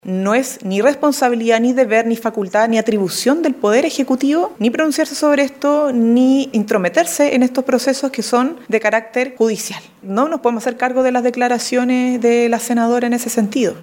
Consultada por Radio Bío Bío, Vallejo aseguró que son los tribunales electorales los responsables de analizar y fallar en este tipo de casos, intentando alejar la polémica de las atribuciones que hoy tiene el Poder Ejecutivo.